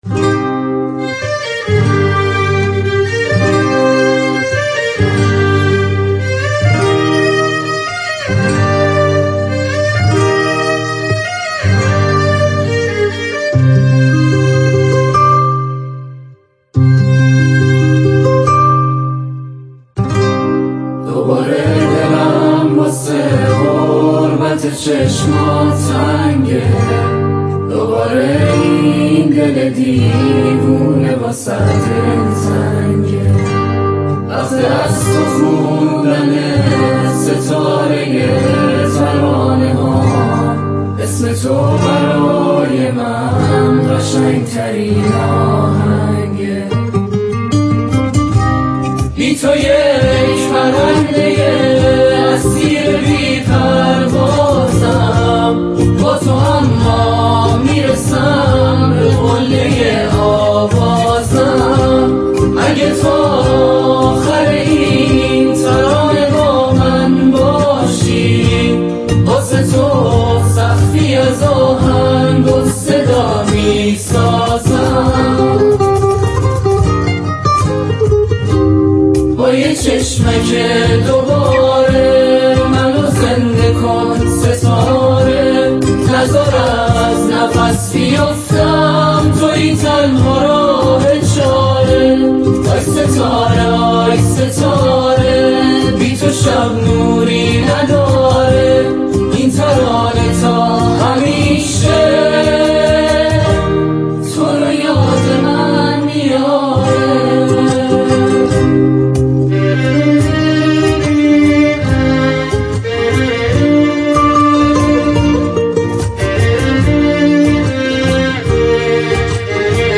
اجرای گروهی